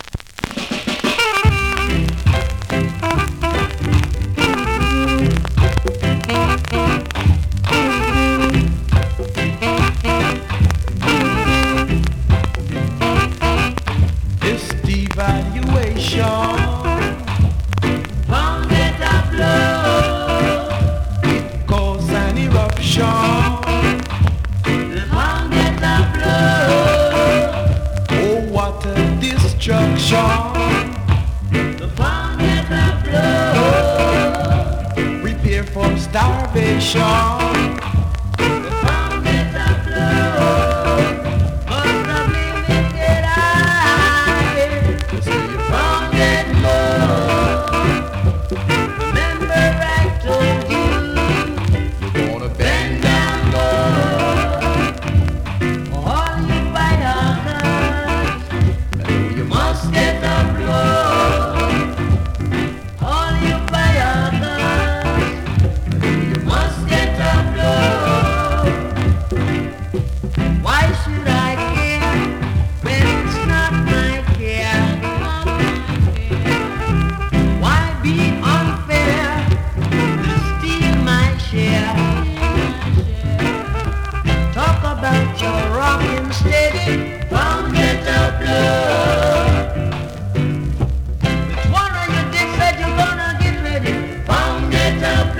コメントレアROCKSTEADY!!
スリキズ、ノイズそこそこあります。